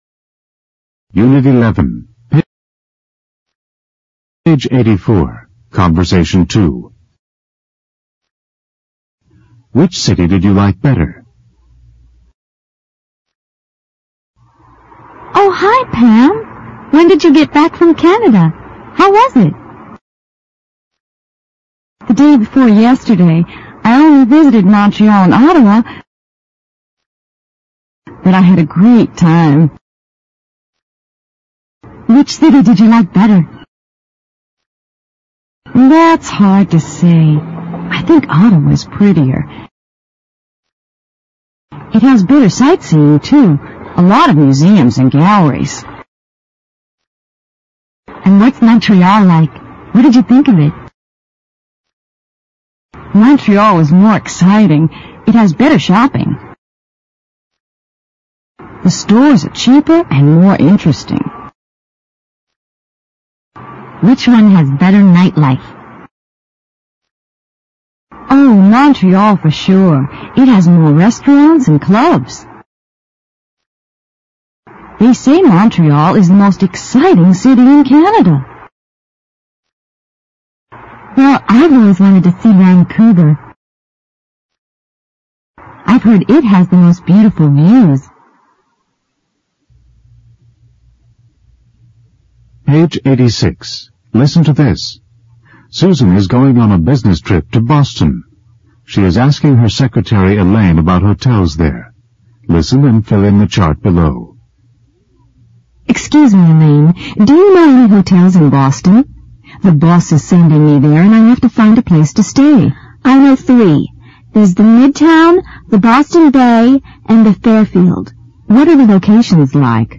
简单英语口语对话 unit11_conbersation2_new(mp3+lrc字幕)